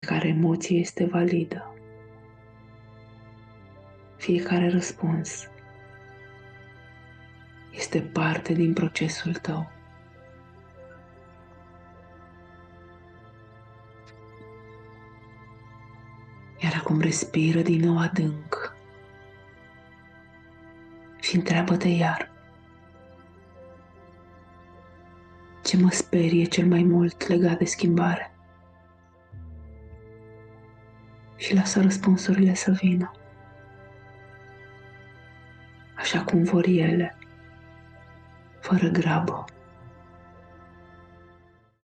Meditatie audio Ghidata